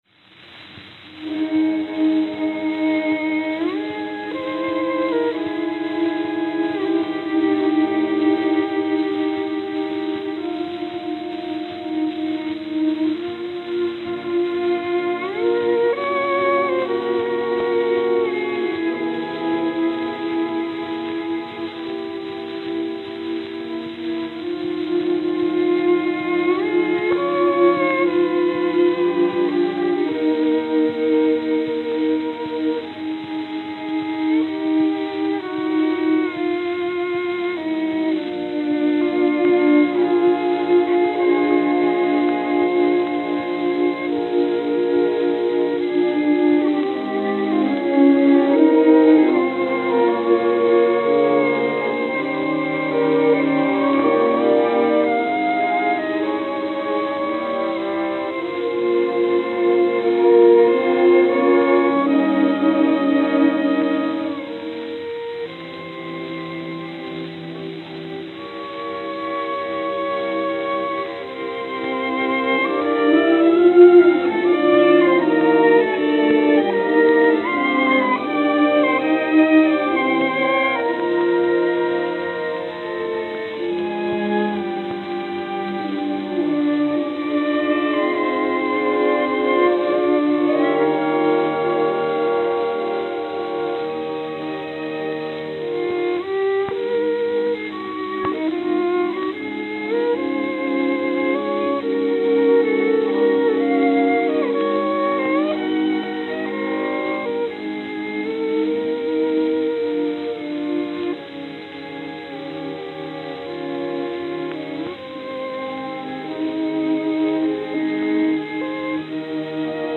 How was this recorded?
Note: Hairline crack about one minute in, audible.